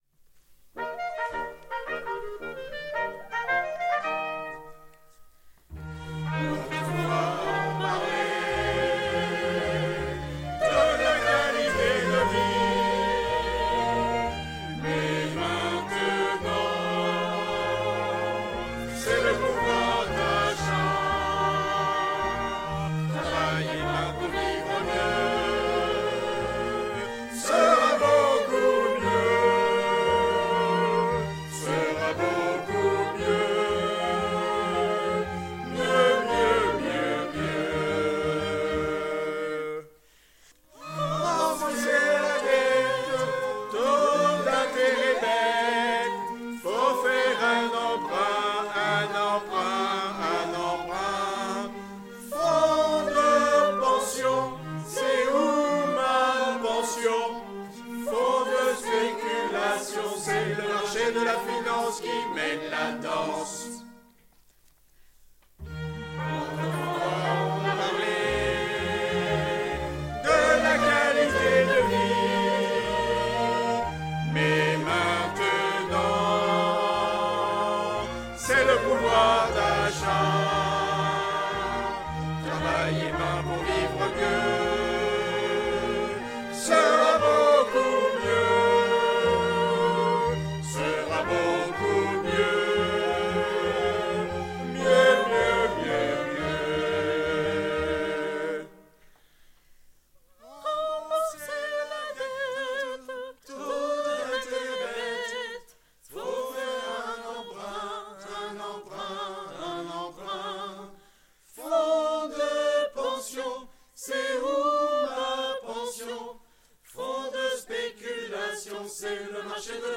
Various market people, oligarchs and passers by. -  Chorus.
No 13 Choeur des marchands.mp3